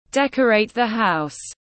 Trang trí nhà cửa tiếng anh gọi là decorate the house, phiên âm tiếng anh đọc là /ˈdek.ə.reɪt ðiː haʊs/
Decorate the house /ˈdek.ə.reɪt ðiː haʊs/